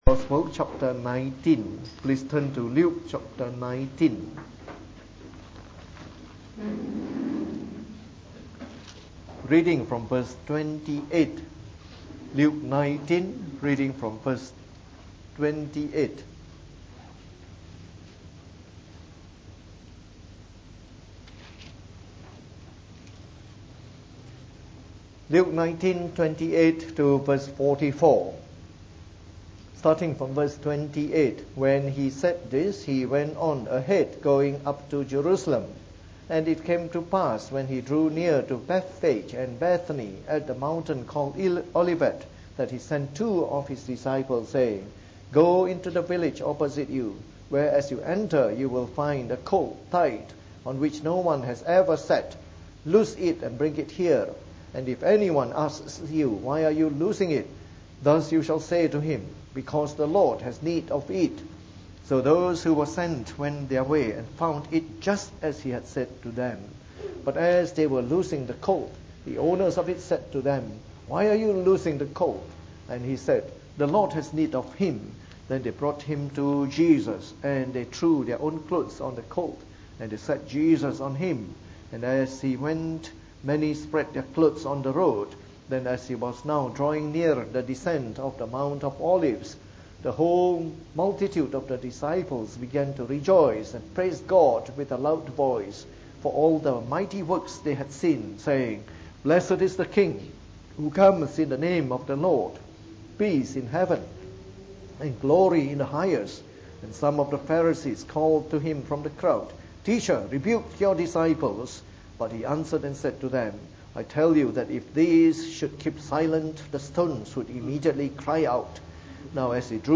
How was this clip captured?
From our series on the “Gospel According to Luke” delivered in the Evening Service.